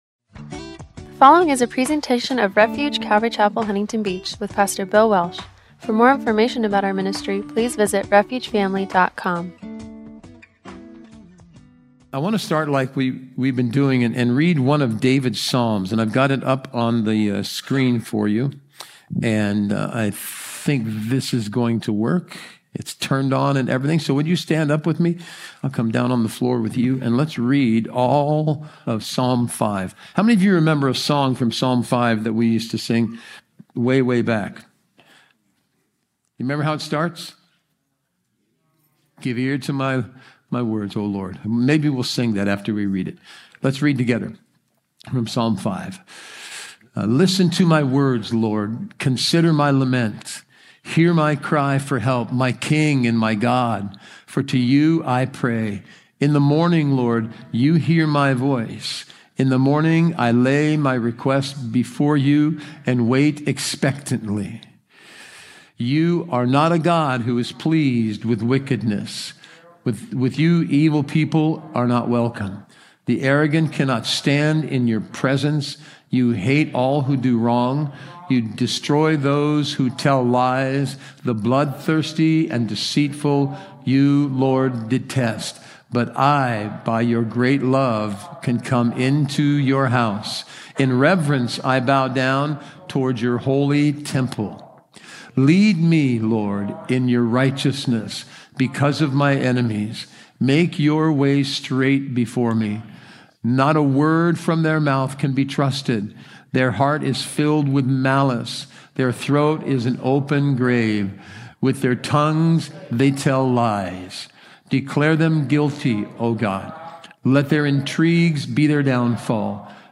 “David’s New Job”-1 Samuel 18:10-16 – Audio-only Sermon Archive
Service Type: Wednesday Night